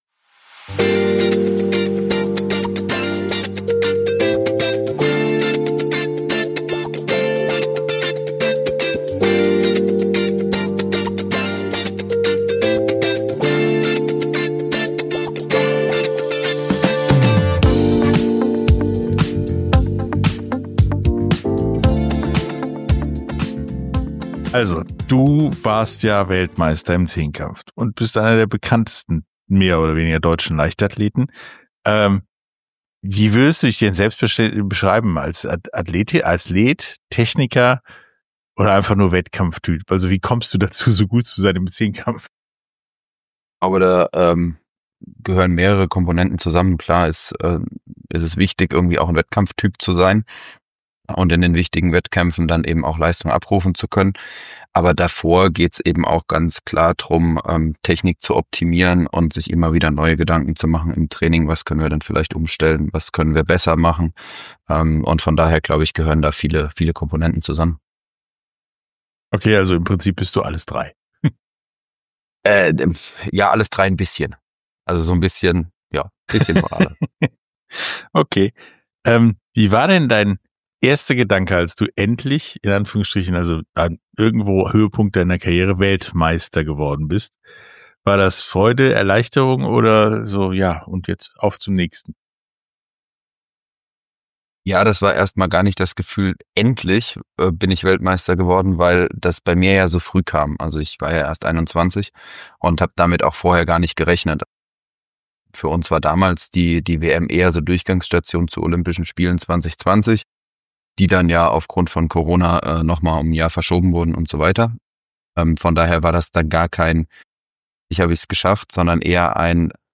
Beschreibung vor 3 Wochen In diesem Interview spricht Niklas Kaul, ehemaliger Weltmeister im Zehnkampf, über die mentale und körperliche Herausforderung seiner Sportart, über Rückschläge und Triumphe – und warum der Wecker am zweiten Tag oft der härteste Gegner ist. Er erzählt, wie er mit nur 21 Jahren überraschend Weltmeister wurde, warum für ihn Technik und mentale Stärke wichtiger sind als reine Kraft, und wie er mit Enttäuschungen bei Olympia umgeht. Außerdem spricht Kaul über das deutsche und das amerikanische Trainingssystem, seine Zukunftspläne – und was ihn am Zehnkampf bis heute fasziniert.